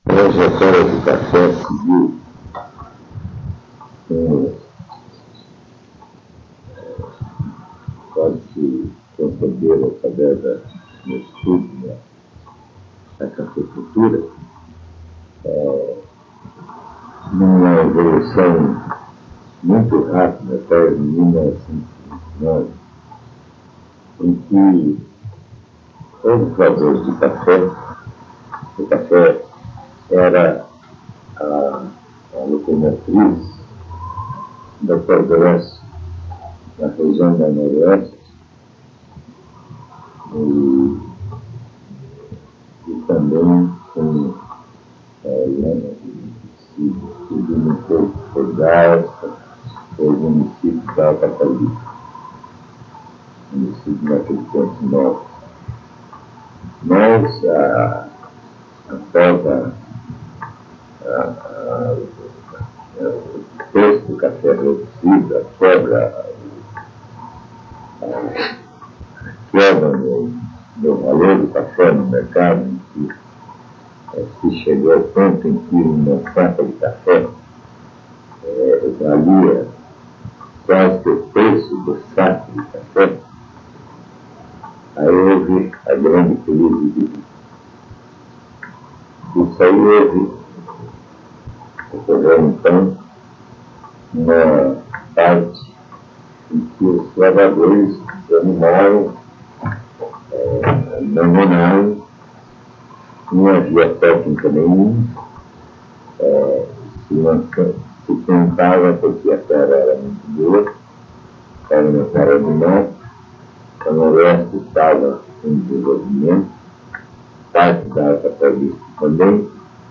Entrevista
*Recomendado ouvir utilizando fones de ouvido.